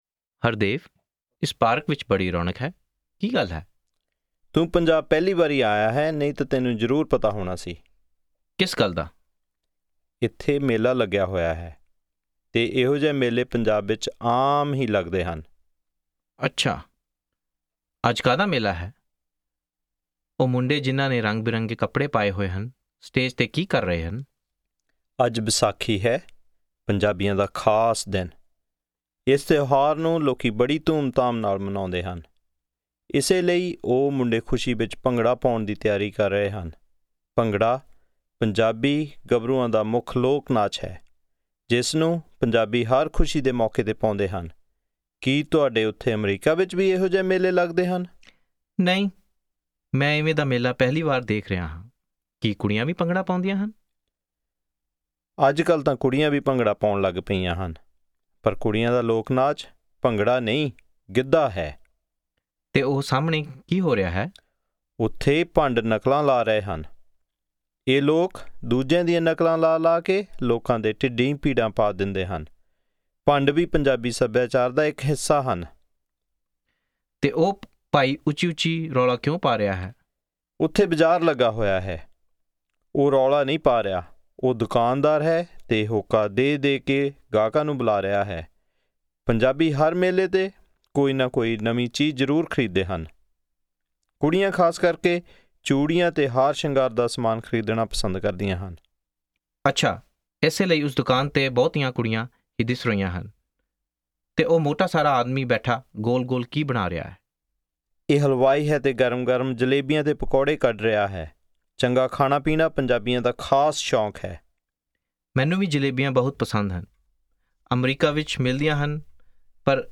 Punjabi Conversation 9 Listen